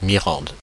Mirande (French pronunciation: [miʁɑ̃d]
Fr-Mirande.oga.mp3